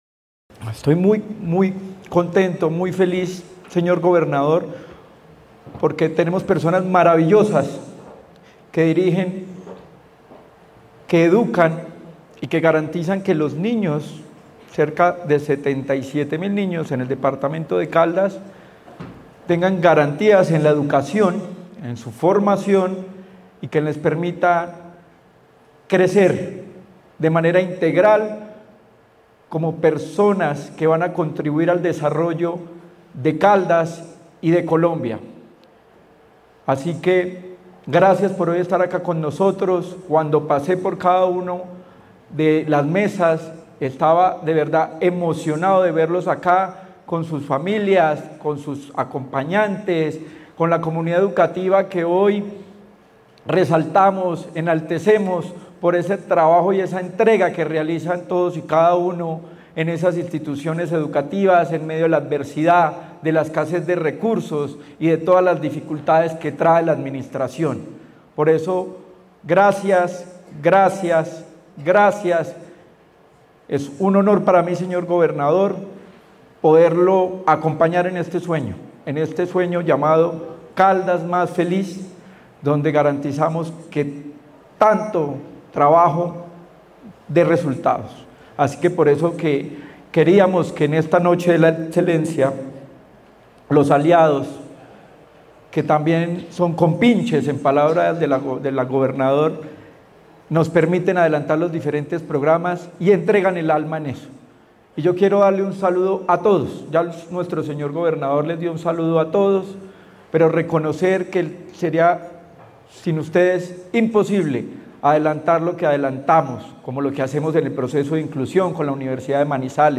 Secretario de Educación de Caldas, Luis Herney Vargas Barrera.